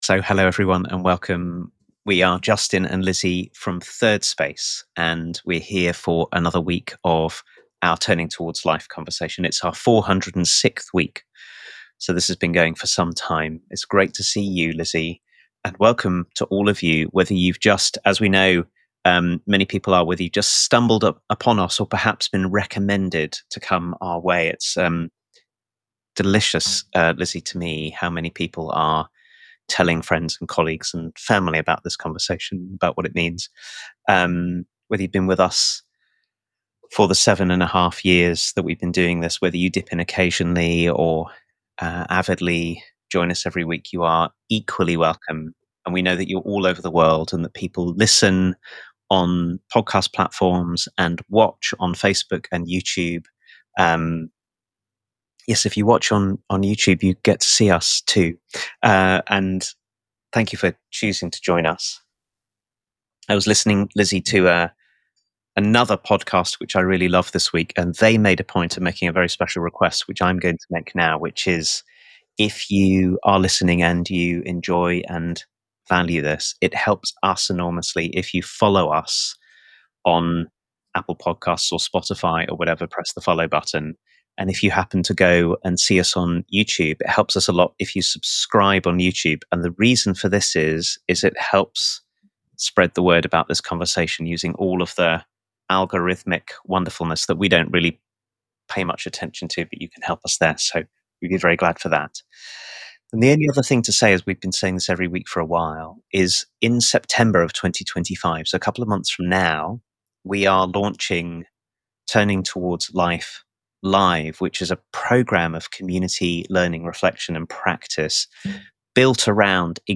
In this conversation we talk about what it takes to do this, and about the Enneagram, a wise, kind and deep body of language and invitation that we have both found incredibly helpful in walking this path.